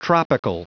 Prononciation du mot tropical en anglais (fichier audio)